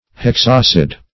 Search Result for " hexacid" : The Collaborative International Dictionary of English v.0.48: Hexacid \Hex`ac"id\, a. [Hex- + acid.]